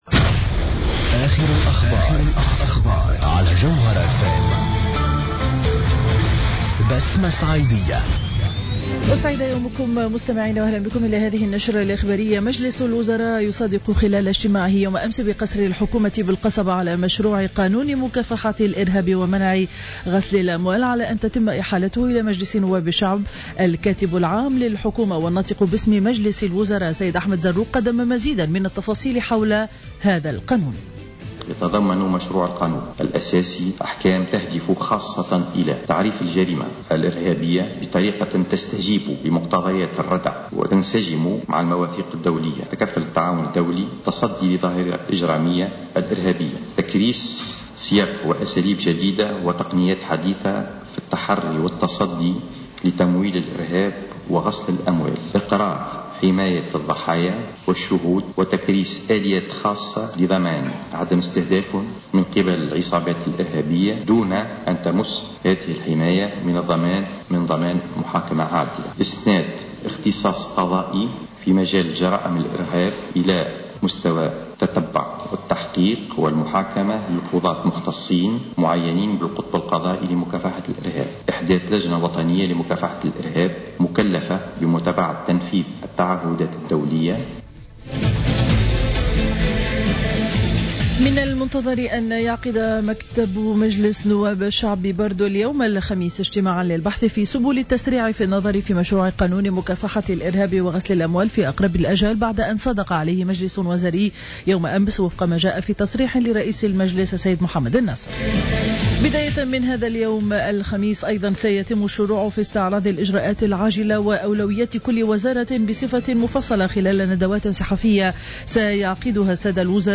نشرة أخبار السابعة صباحا ليوم الخميس 26 مارس 2015